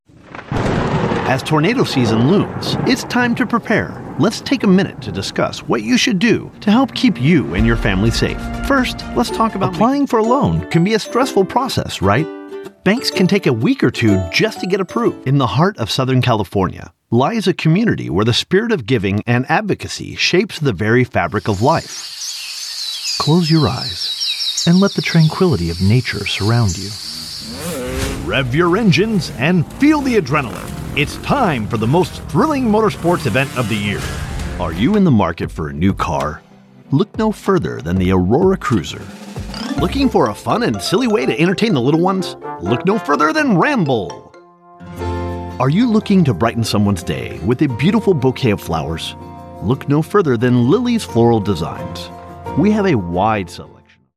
A versatile voice actor providing broadcast-quality narration and commercial voiceover from my professional home studio.
Voiceover Reel
English - Western U.S. English
Middle Aged
I record from my home studio equipped using professional equipment to ensure clean, consistent, broadcast-quality audio on every project.